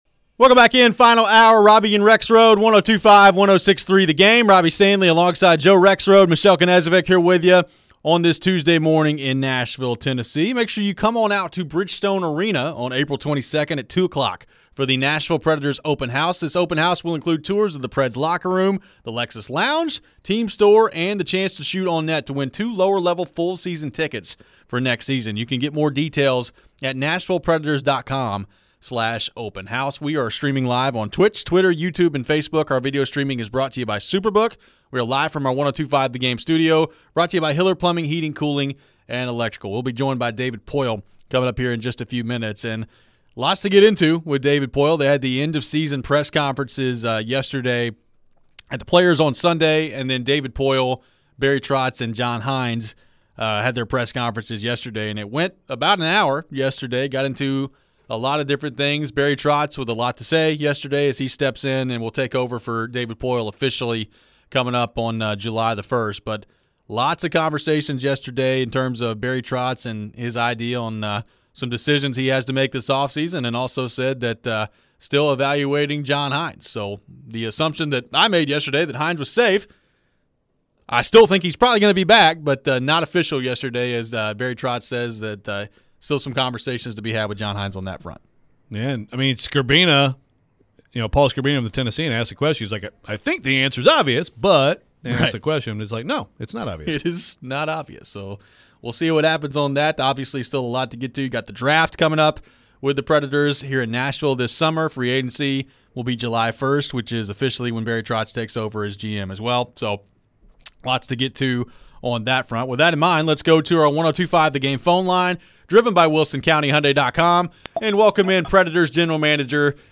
David Poile Interview (4-18-23)
Nashville Predators GM David Poile joined the program for the final time this year as the Stanley Cup Playoffs are underway.